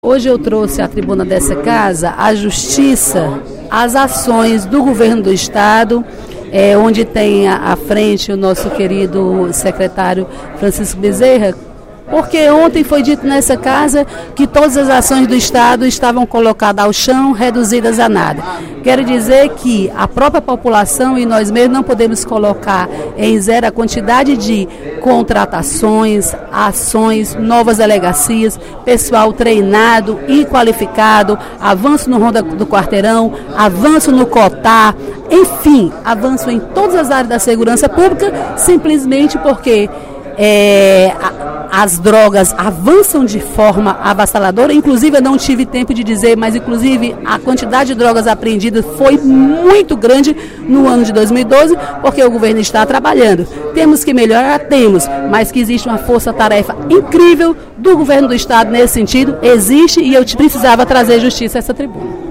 No primeiro expediente da sessão plenária da Assembleia Legislativa desta quinta-feira (07/02), a deputada Dra. Silvana (PMDB) destacou ações do Governo do Estado na área da segurança pública.